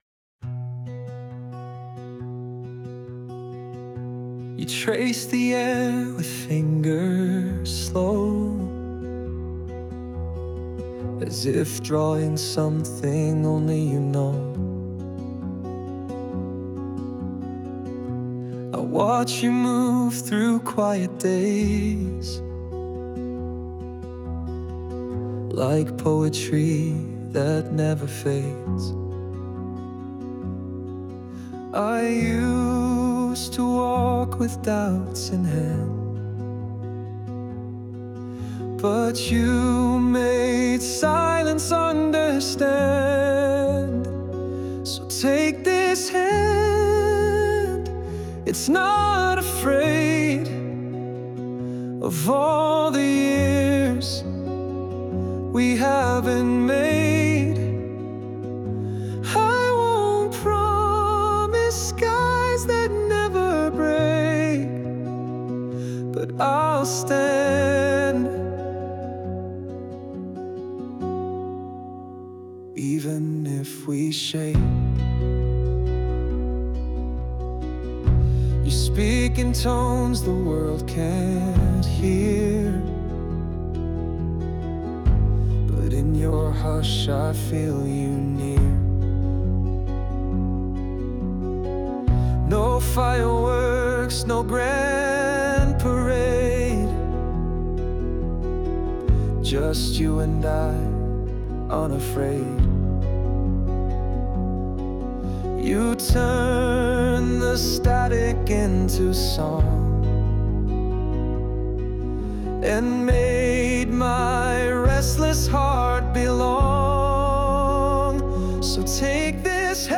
男性ボーカル洋楽洋楽 男性ボーカルプロフィールムービーエンドロールお手紙朗読・花束贈呈バラード優しい
男性ボーカル（洋楽・英語）曲です。